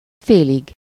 Ääntäminen
Synonyymit moitié mèche pinte Ääntäminen France: IPA: [də.mi] Haettu sana löytyi näillä lähdekielillä: ranska Käännös Ääninäyte Adjektiivit 1. félig Suku: m .